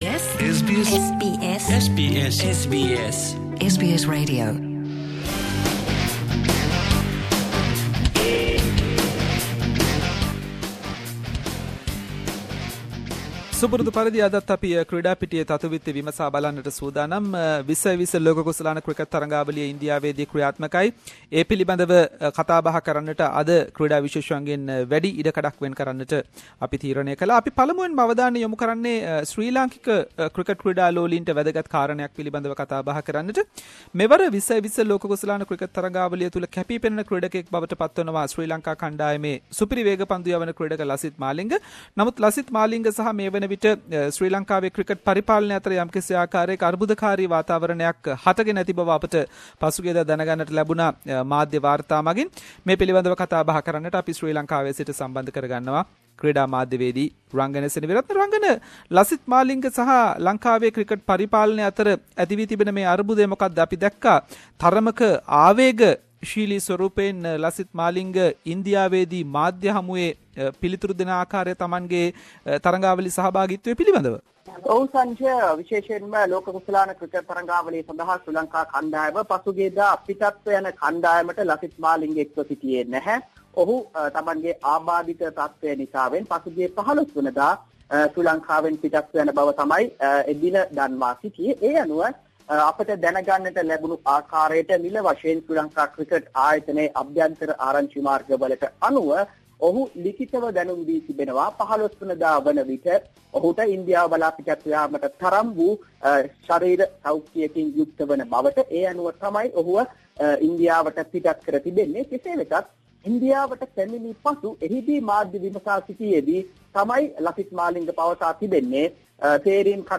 In this weeks SBS Sinhalese sports wrap…. Latest from T20 World cup and related stories.